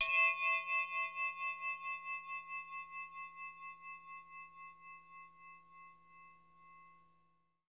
Index of /90_sSampleCDs/Roland LCDP03 Orchestral Perc/PRC_Orch Toys/PRC_Orch Triangl
PRC TREM.0DR.wav